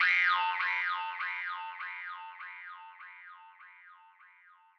描述：西伯利亚犹太人的竖琴
Tag: 100 bpm Ambient Loops Woodwind Loops 826.96 KB wav Key : Unknown